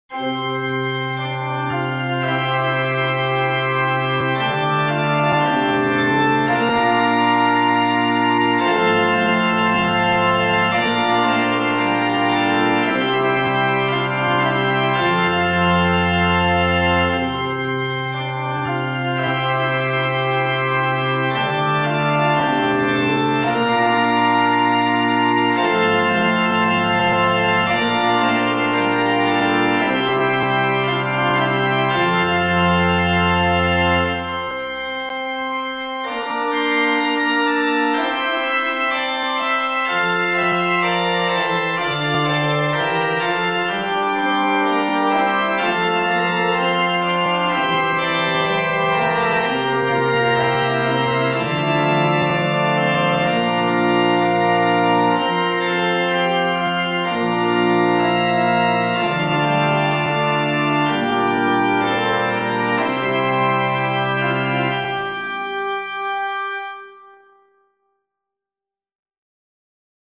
organo.mp3